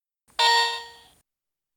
Ding (Penguin Snow Globe Game)
alert ding effect fx game noise notification penguin sound effect free sound royalty free Sound Effects